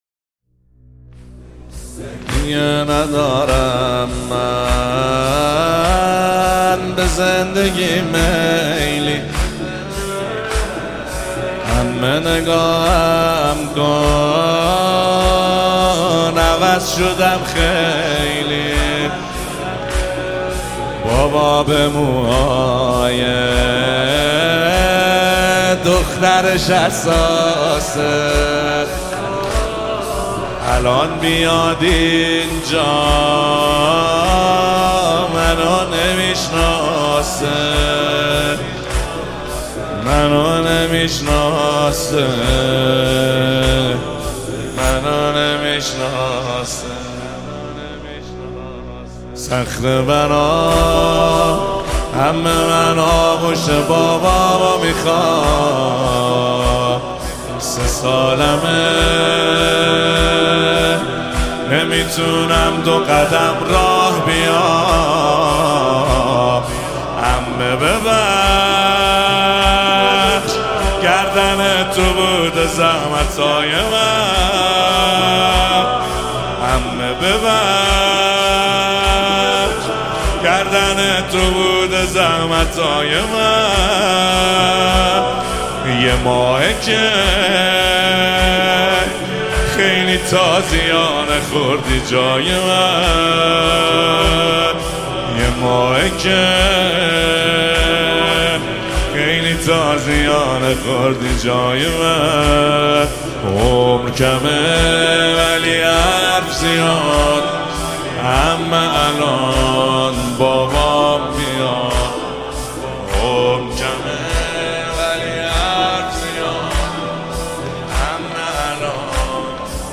مداحی احساسی استودیویی